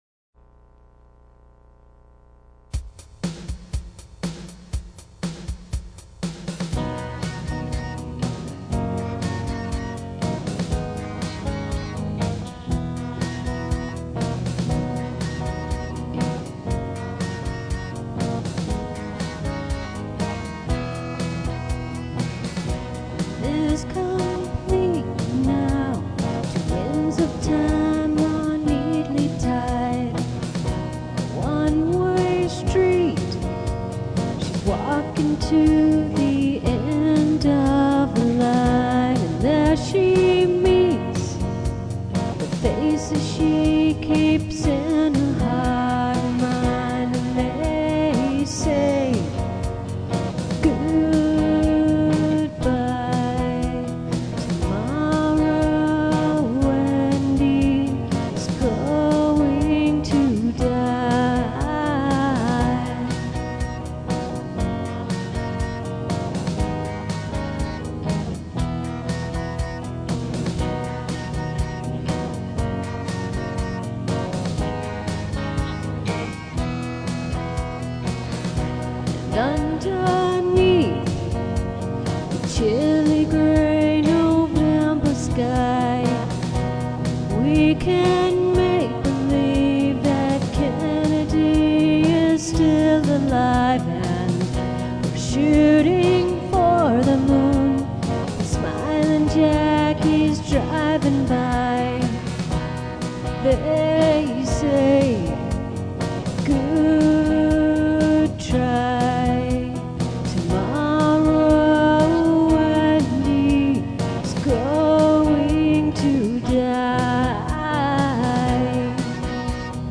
coversong
guitar, voice, violin